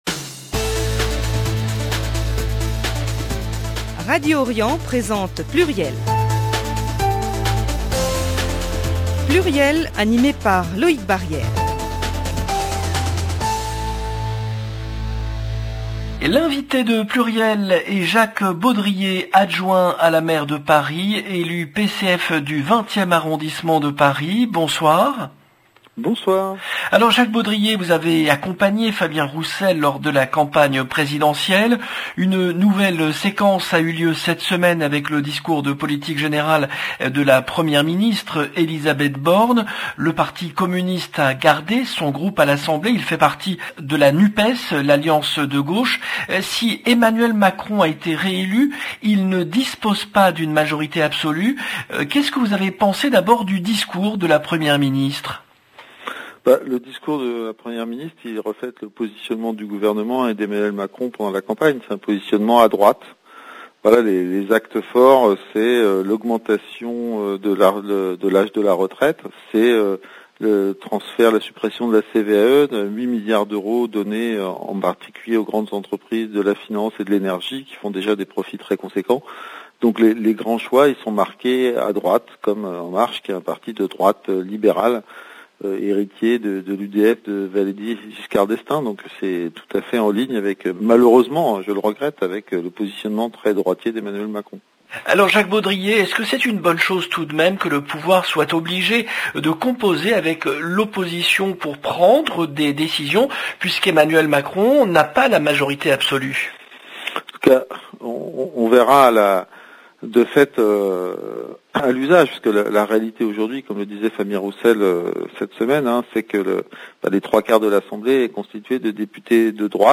L’invité de PLURIEL est Jacques Baudrier, adjoint à la maire de Paris, élu PCF du 20e arrondissement de Paris